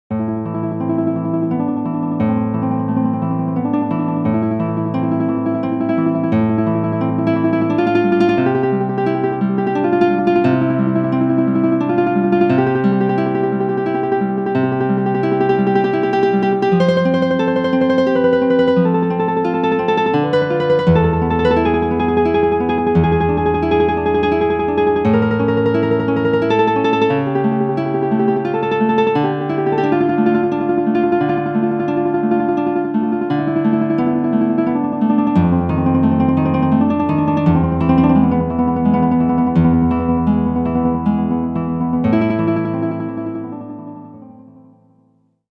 Guitar Patch Demo.
hp-207_demo_guitar.mp3